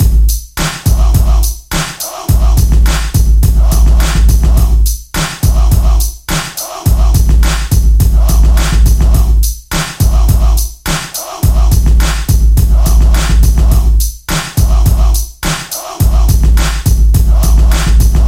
说唱节拍
Tag: 105 bpm Rap Loops Drum Loops 3.08 MB wav Key : Unknown